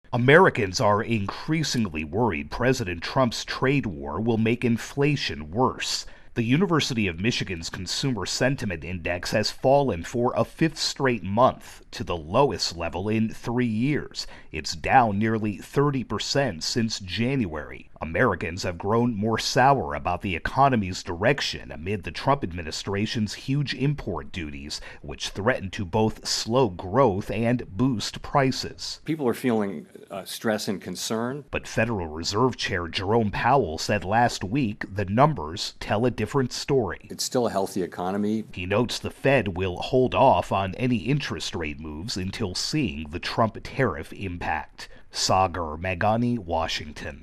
reports on another dip in consumer sentiment.